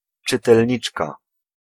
Ääntäminen
IPA: [lɛk.tœʁ]